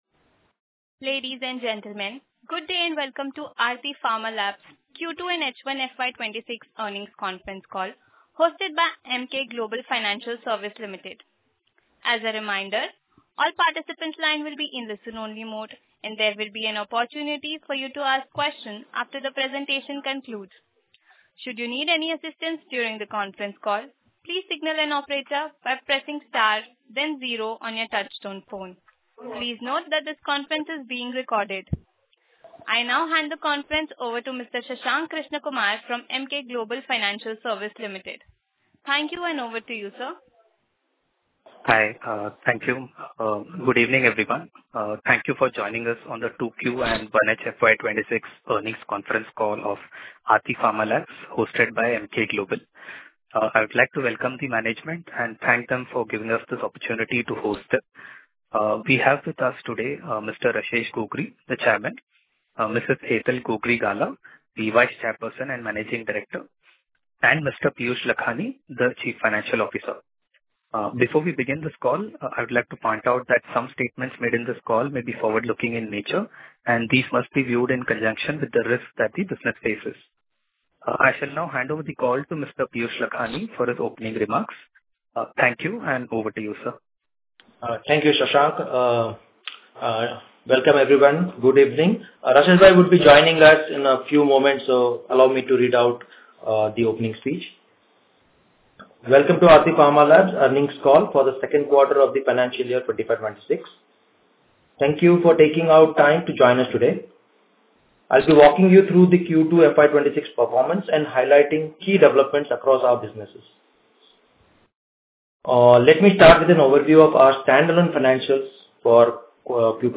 q2-fy-26-earnings-concall.mp3